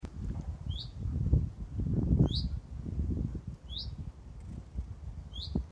Guinea pig soundalike?
What bird is making this short call that sounds a little like a guinea pig squeaking?